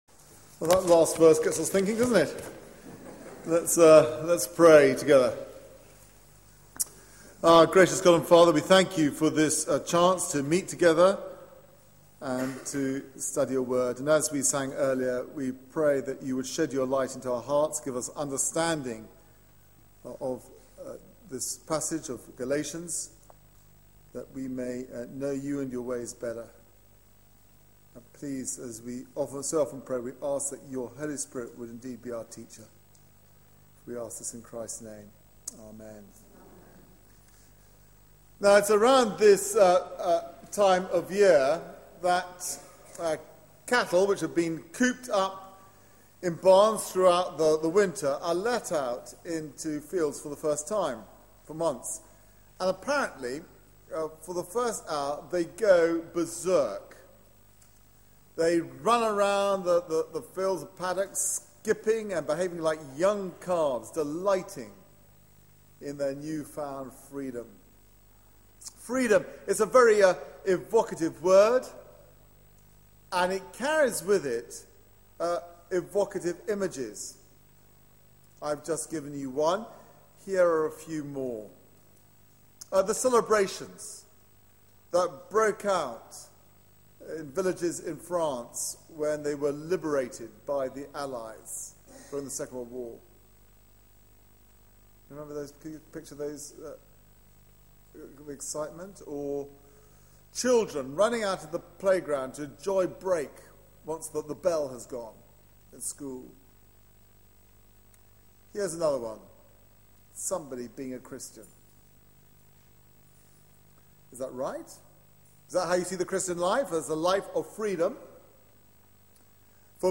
Media for 9:15am Service on Sun 03rd Mar 2013 09:15 Speaker
Sermon